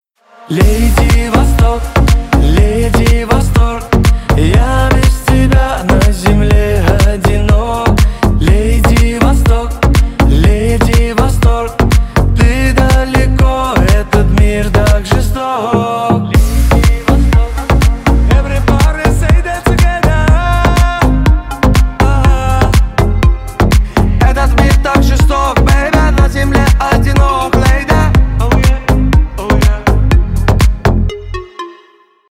бесплатный рингтон в виде самого яркого фрагмента из песни
Поп Музыка
клубные